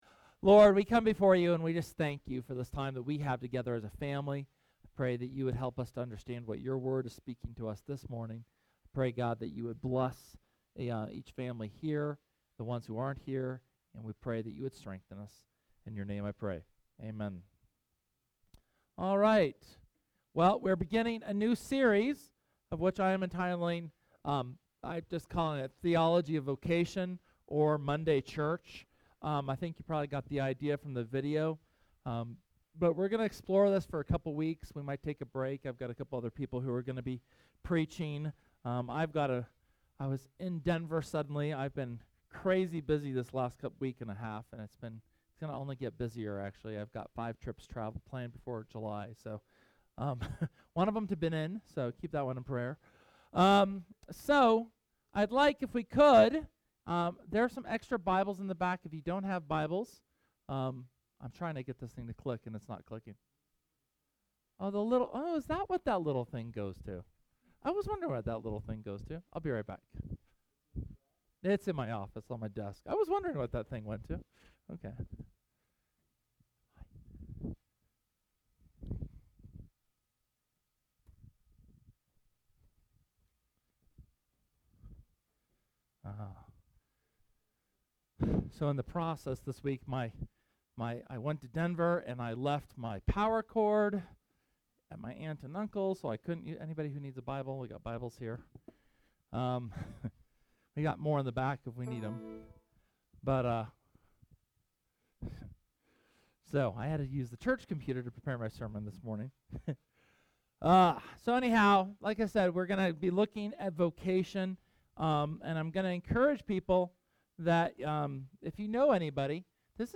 First sermon in a series on the theology of our vocation.